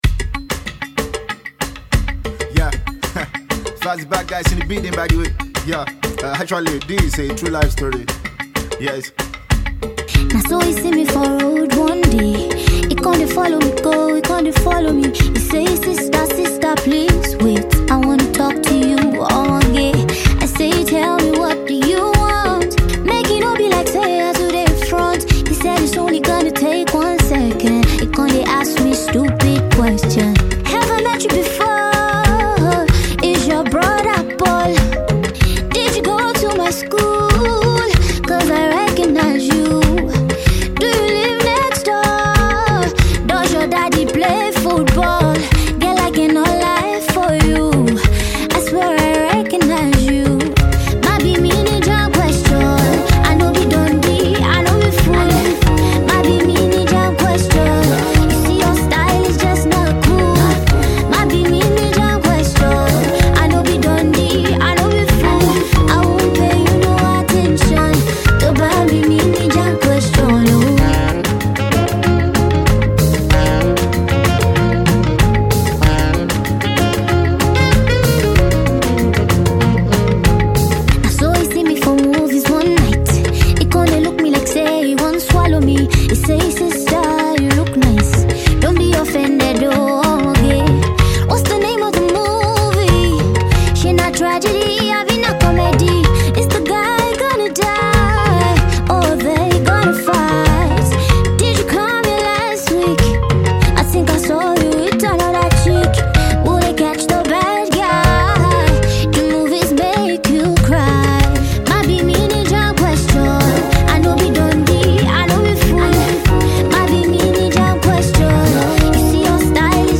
the RnB sweetheart has dropped the full version.
witty, comical and romantic.